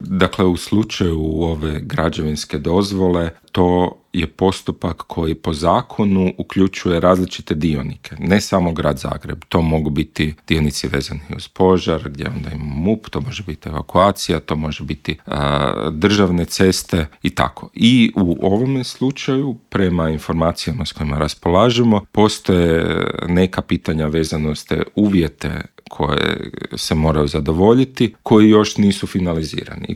Više od 50 tisuća djece u Hrvatskoj suočava se s mentalnim teškoćama, broj djece s teškoćama u zagrebačkim osnovnim školama više se nego udvostručio u zadnjih deset godina, a više od trećine, odnosno čak 36 posto djece u Hrvatskoj je pretilo, dok je to na razini Europe slučaj s njih 25 posto, iznio je alarmantne podatke Hrvatskog zavoda za javno zdravstvo i UNICEF-a u Intervjuu Media servisa pročelnik Gradskog ureda za obrazovanje Luka Juroš i poručio: